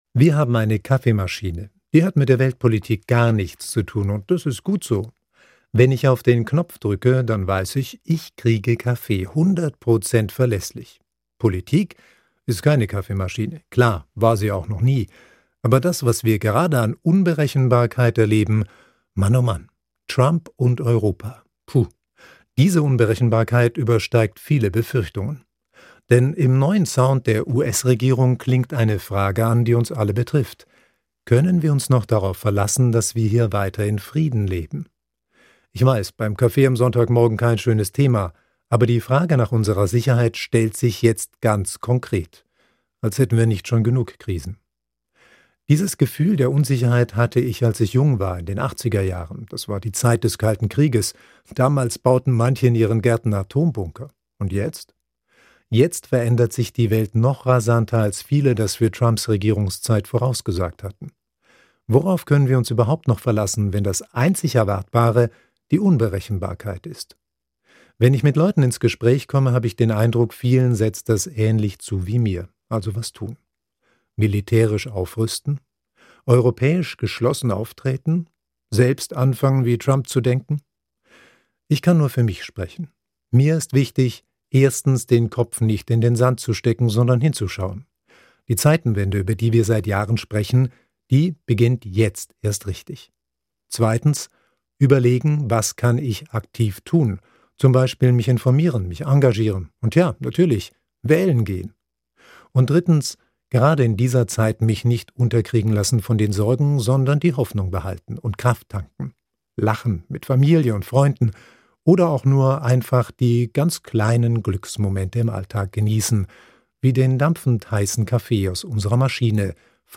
Kriegsgräberfürsorge auf dem Karlsruher Hauptfriedhof. Gespräch